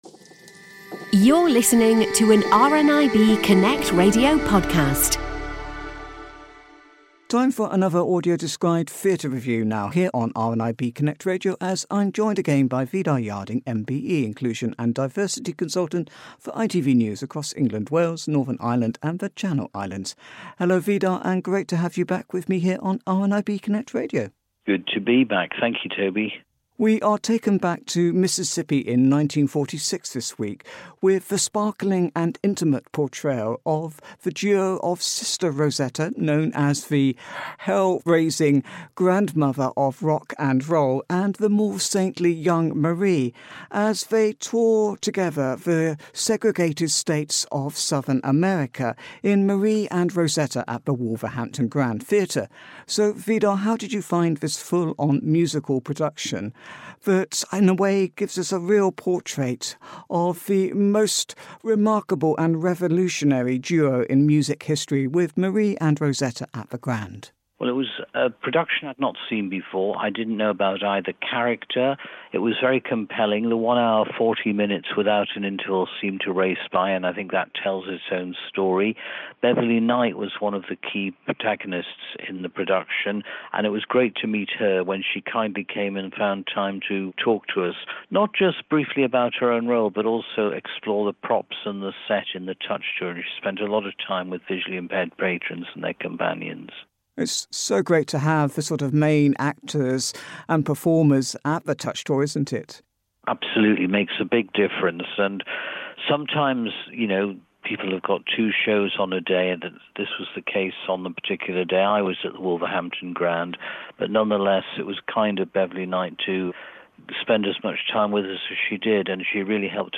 Marie & Rosetta, AD Theatre Review